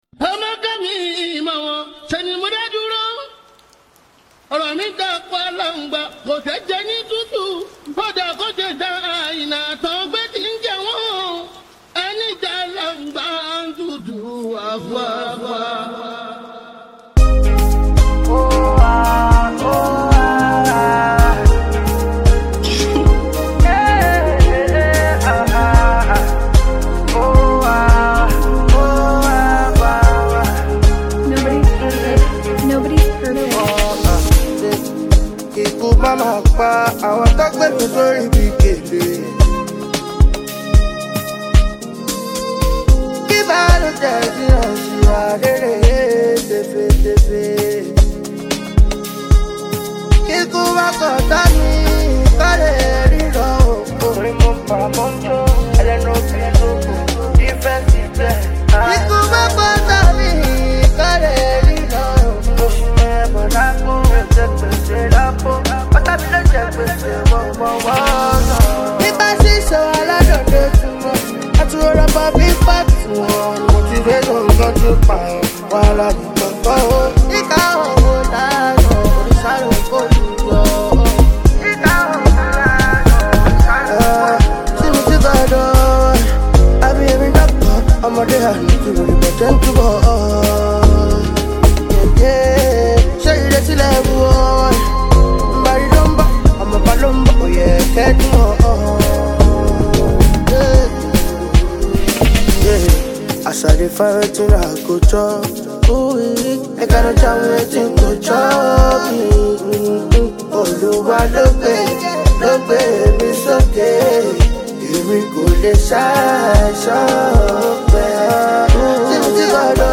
the acclaimed and exceptionally talented Afrobeats sensation